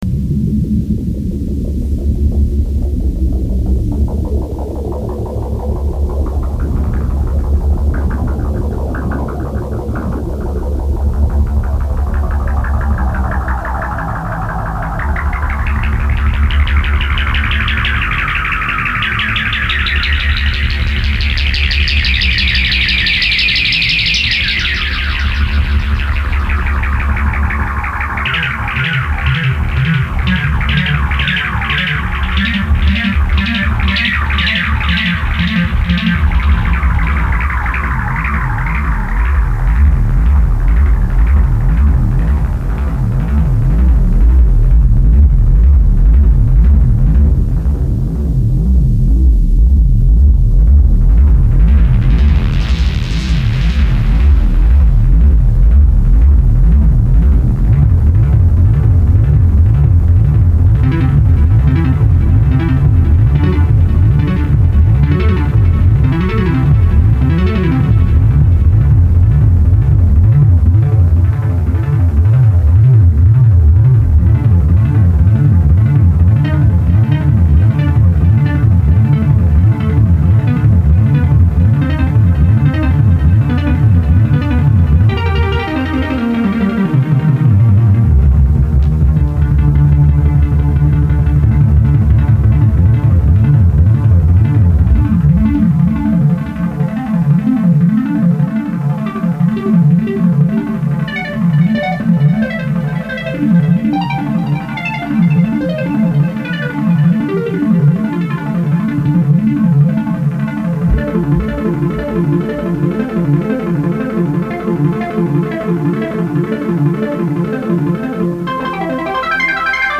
Sequencial Circuits Pro-One Synth